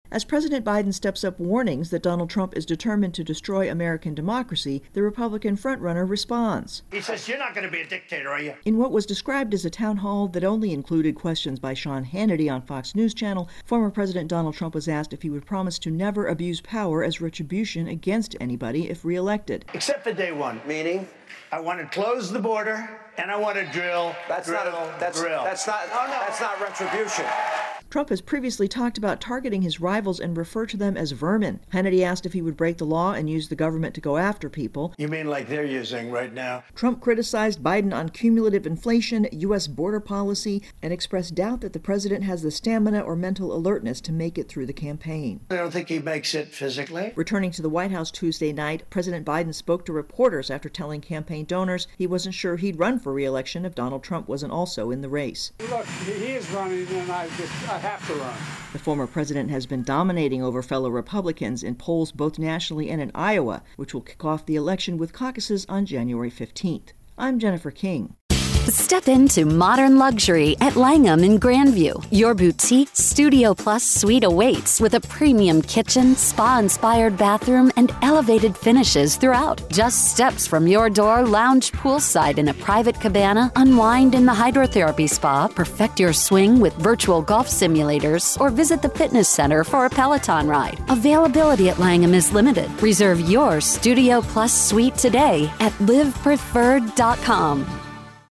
(Wrap version, voicer sent earlier)